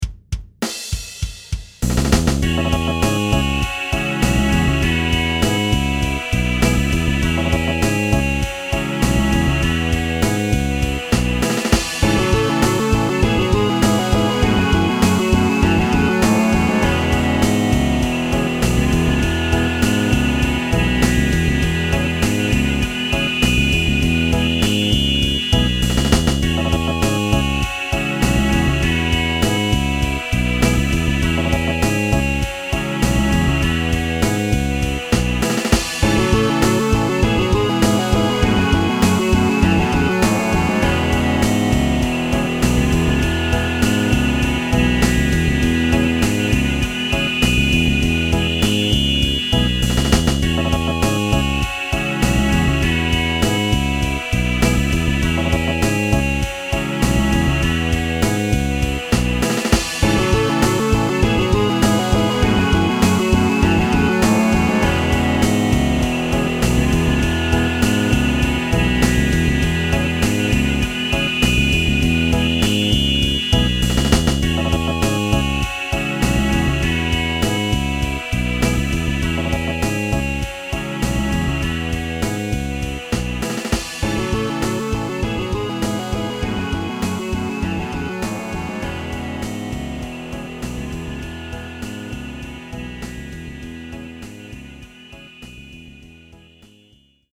Frankly, the hi-bandwidth version sounds much better than the lo-res version, so even if you have a dial-up connection, listen to the hi-res...
- (hi-bandwidth / stereo) - will pop up in a new window
For the record, nobody played anything on this tune - it's all sampled and scored in Reason.